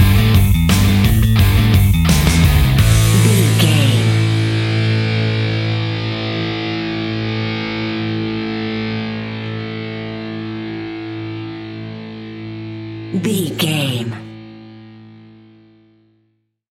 Epic / Action
Fast paced
Aeolian/Minor
hard rock
blues rock
instrumentals
Rock Bass
heavy drums
distorted guitars
hammond organ